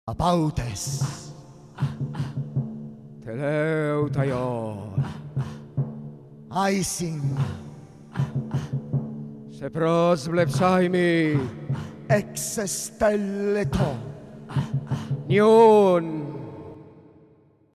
per stati complementari della voce
In OPERA – interamente composta sul testo in greco antico – ogni posizione precedente in merito all'uso del corpo ritmico e sonoro della lingua si radicalizza nella totale rinuncia al significato veicolato dalla parola.